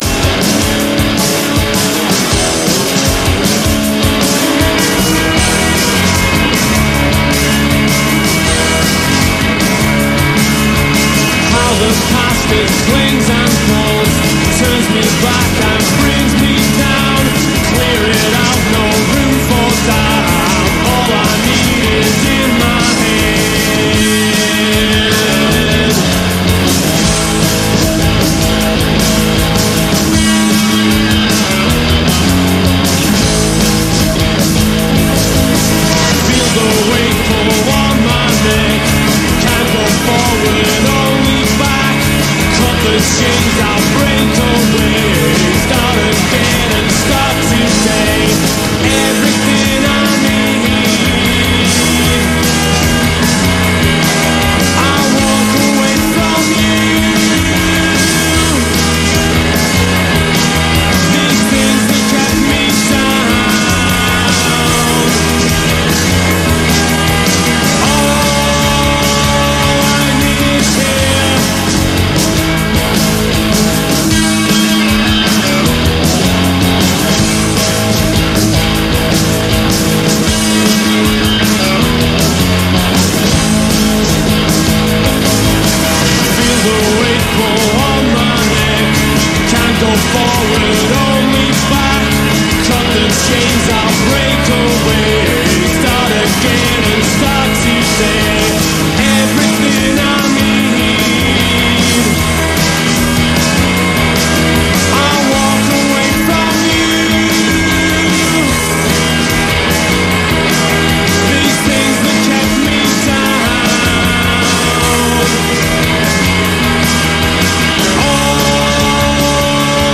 of the oddly Morrissey-esque vocal phrasing
guitar
drums
bass